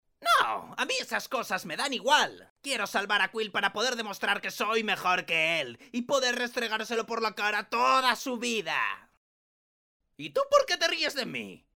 Voz masculina en español de edad adulta. Tono agudo e interpretación de personaje.